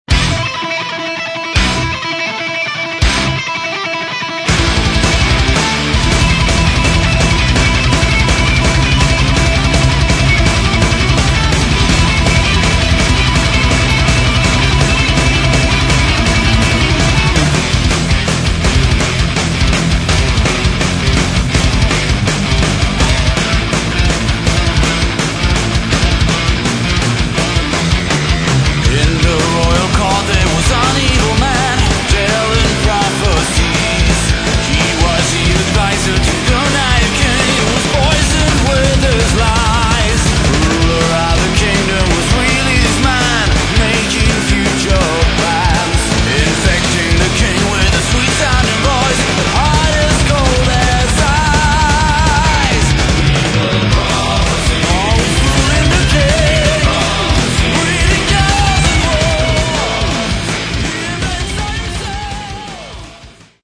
Metal
Древняя битва между Добром и Злом в стиле Speed Power Metal!